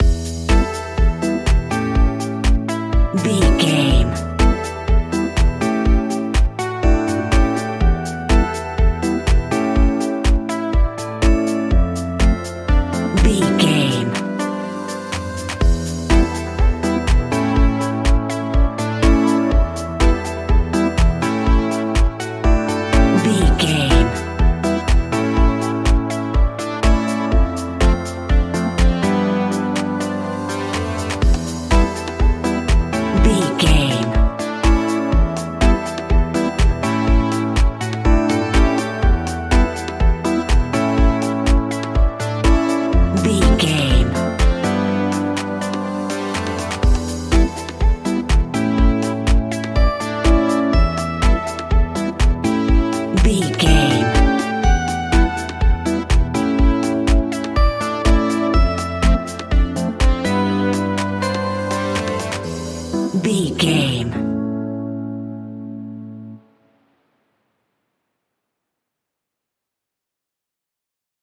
Aeolian/Minor
uplifting
energetic
bouncy
synthesiser
drum machine
electric piano
funky house
nu disco
groovy
upbeat
synth bass